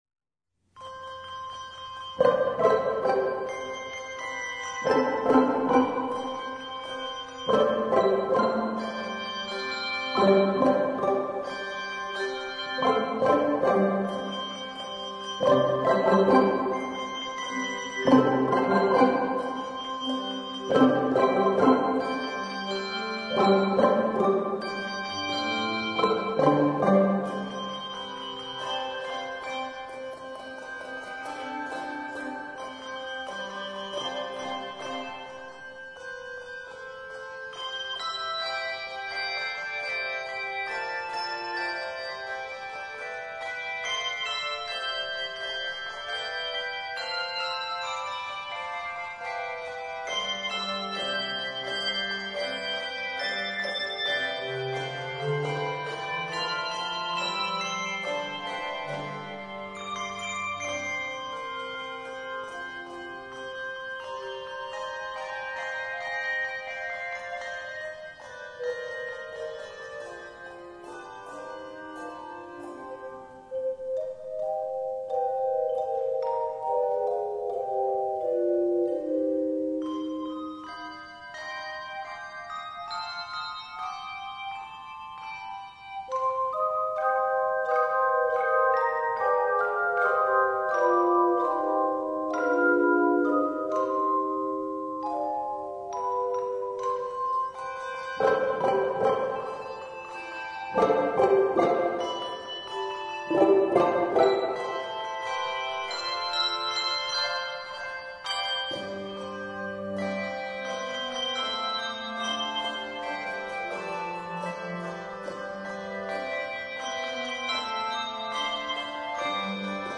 Voicing: Handbells